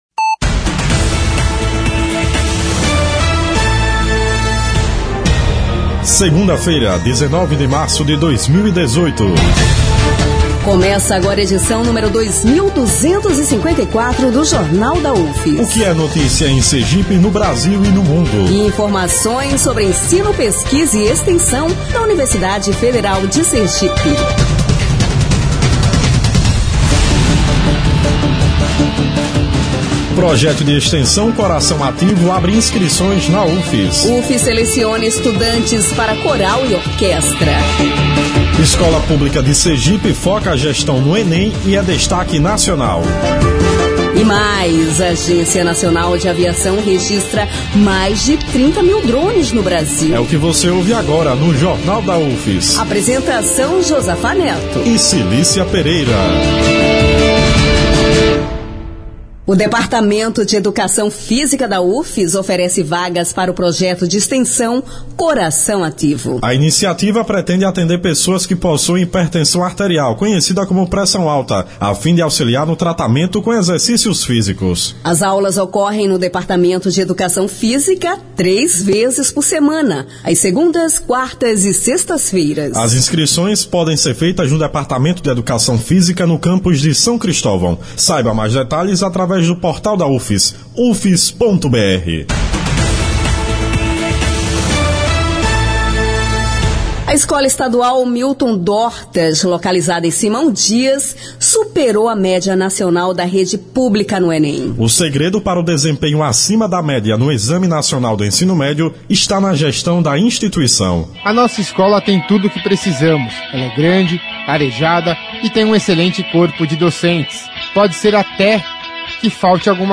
O Jornal da UFS desta segunda-feira, 19, destaca que a gestão com foco no Enem transformou a realidade da Escola Estadual Milton Dortas, em Simão Dias, no interior de Sergipe. O noticiário vai ao ar às 11h na Rádio UFS, com reprises às 17h e 22h.